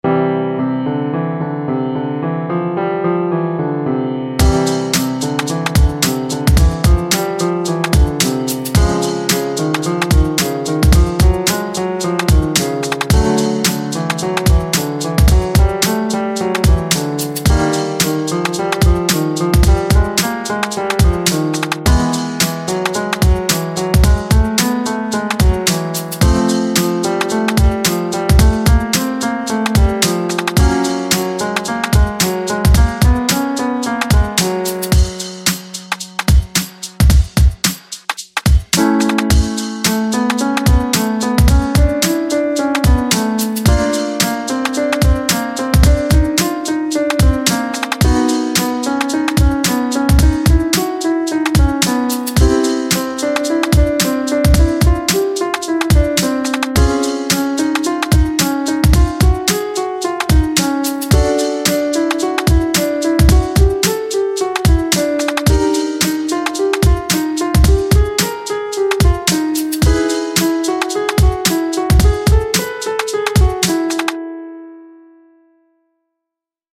وارم آپ ( فایل صوتی رفع تنش زبان )
فایل تمرین تکمیلی پست ایسنتاگرام رفع تنش زبان :